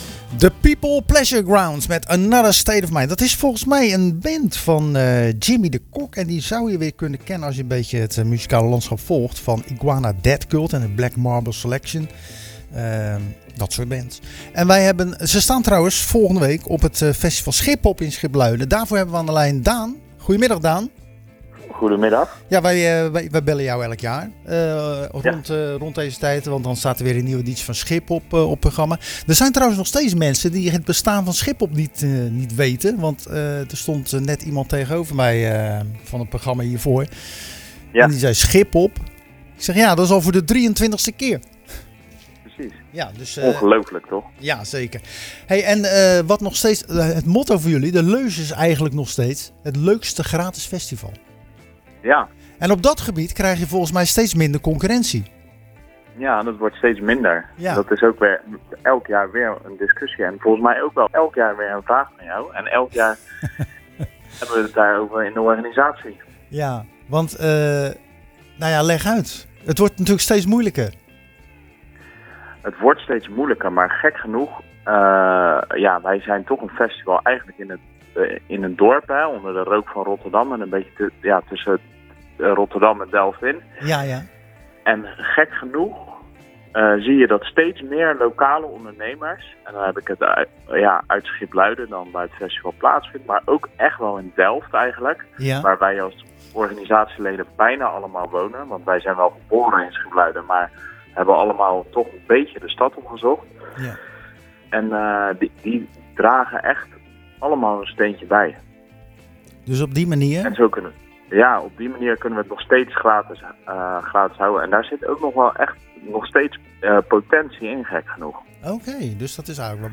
Zoals ieder jaar belde we ook nu weer tijdens de wekelijkse uitzending van Zwaardvis